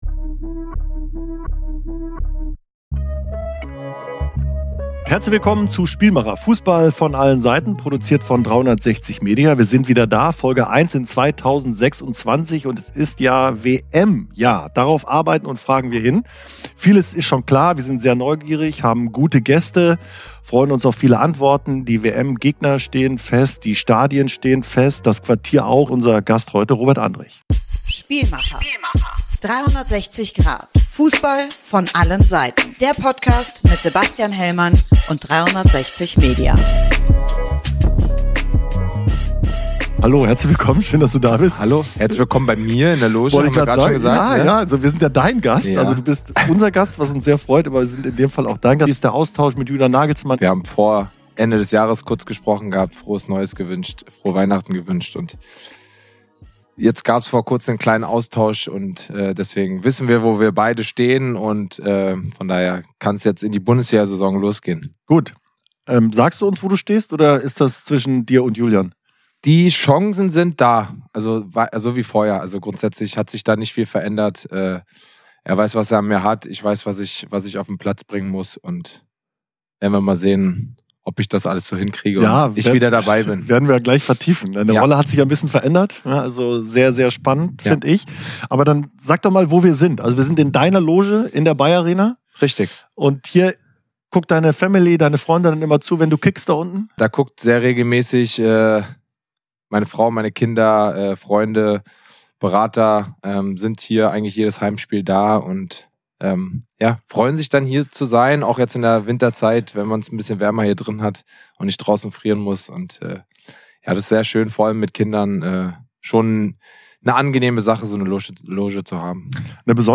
Für „Spielmacher“ hat Andrich Sebastian Hellmann in seine Loge in der BayArena eingeladen. Der Nationalspieler redet über die Vorurteile, die er gegenüber Joshua Kimmich hatte und die besondere Freundschaft, die mittlerweile daraus entstanden ist.